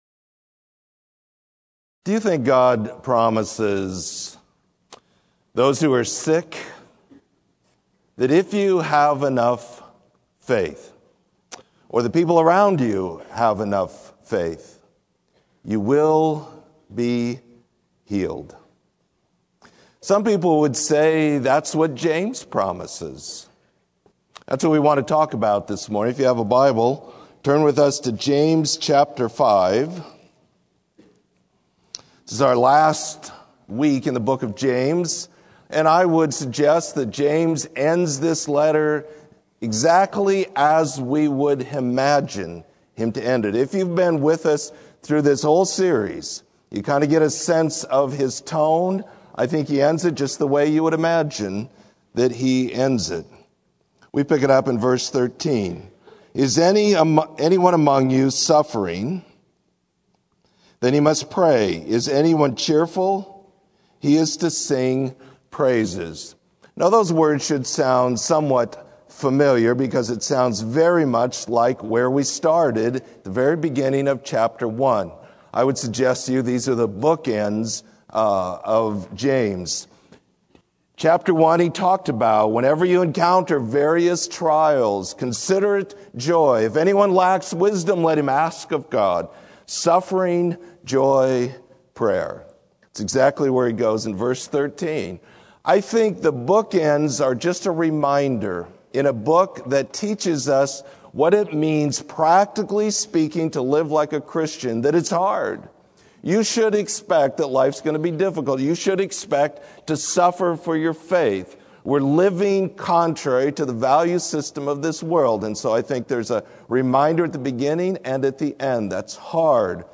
Sermon: Righteous Living